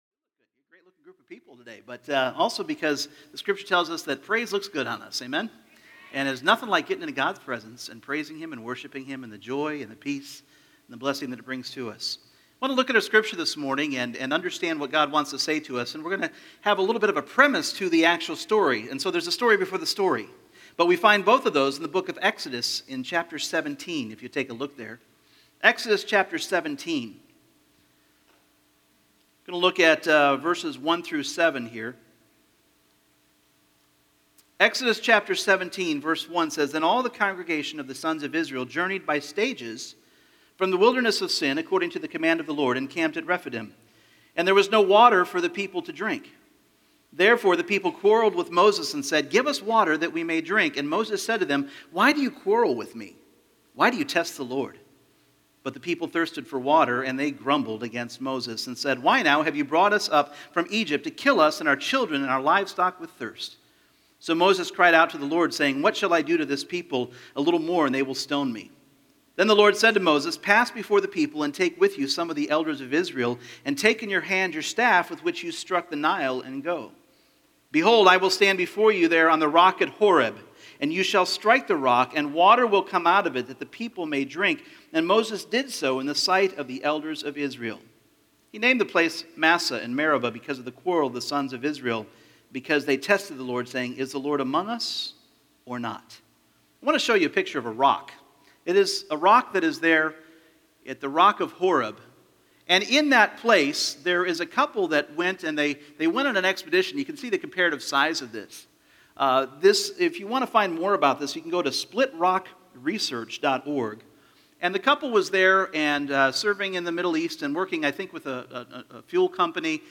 Individual Messages Service Type: Sunday Morning The children of Israel were facing their first battle